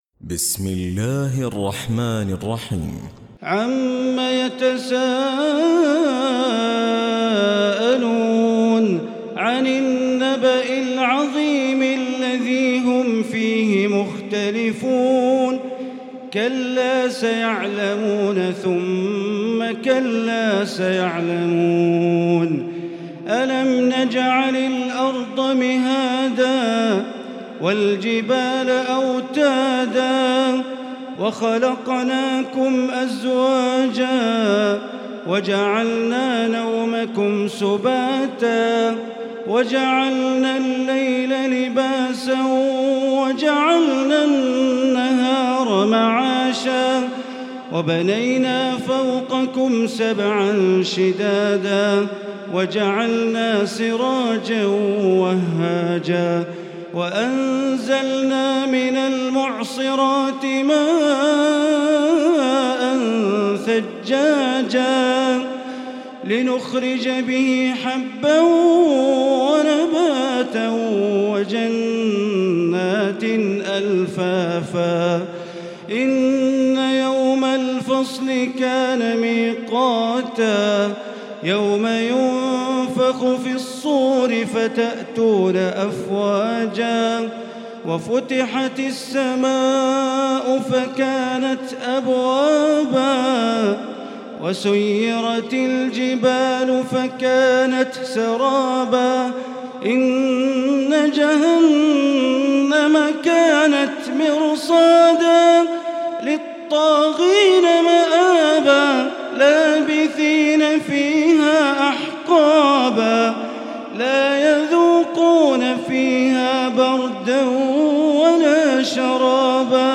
تراويح ليلة 29 رمضان 1438هـ من سورة النبأ الى البينة Taraweeh 29 st night Ramadan 1438H from Surah An-Naba to Al-Bayyina > تراويح الحرم المكي عام 1438 🕋 > التراويح - تلاوات الحرمين